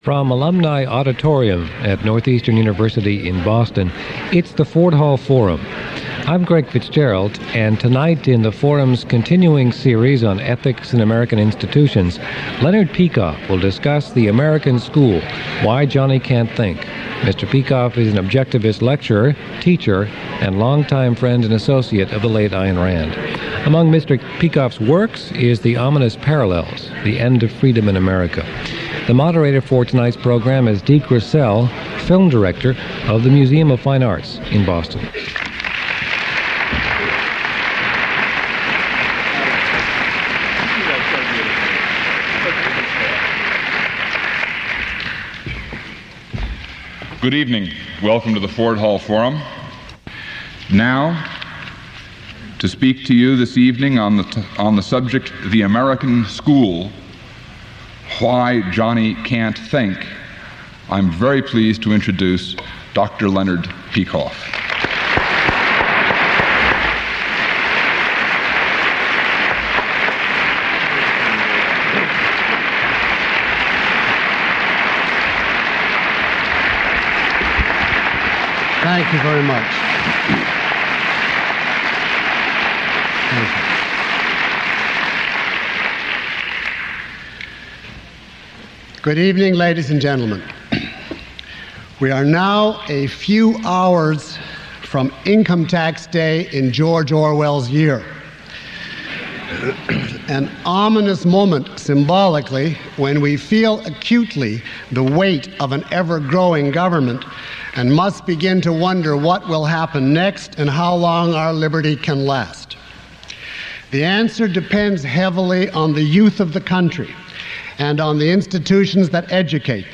Delivered at the Ford Hall Forum in 1984, this lecture examines why the American education system underperforms and fails to properly educate children.
Lecture (MP3) Questions about this audio?